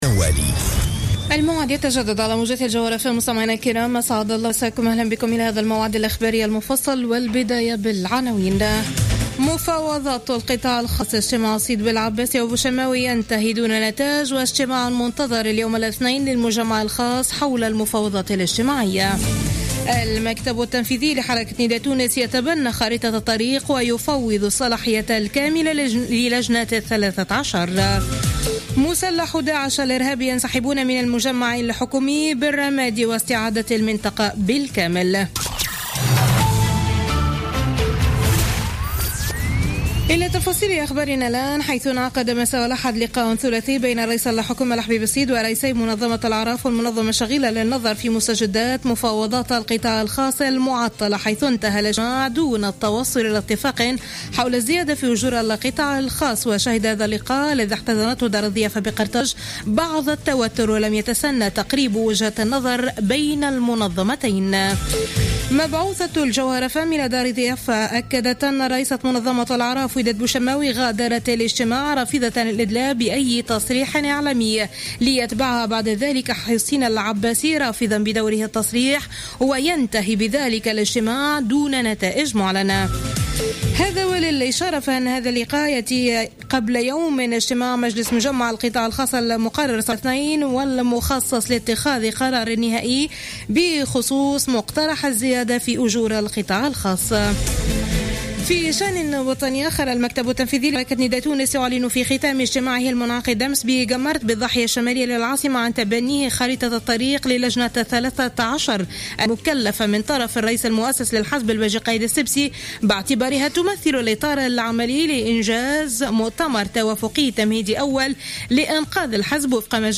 نشرة أخبار منتصف الليل ليوم الإثنين 28 ديسمبر 2015